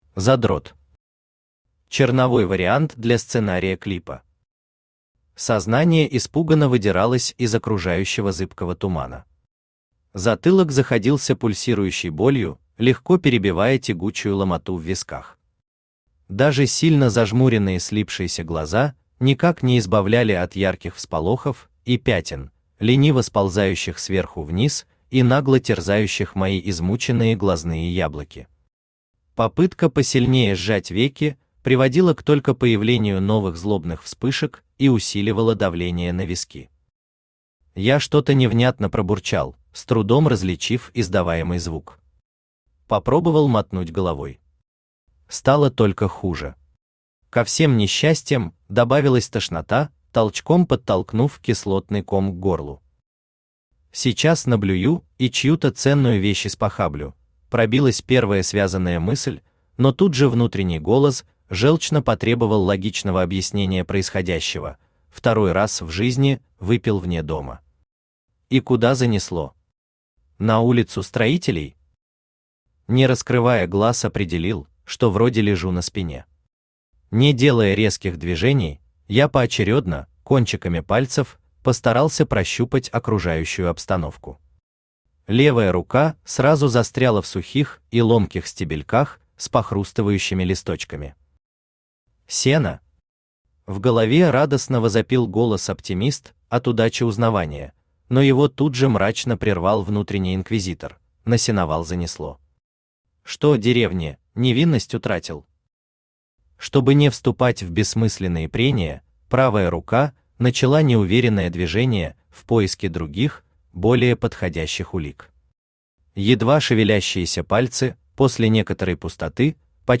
Аудиокнига Задрот | Библиотека аудиокниг
Aудиокнига Задрот Автор Арест Ант Читает аудиокнигу Авточтец ЛитРес.